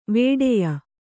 ♪ vēḍeya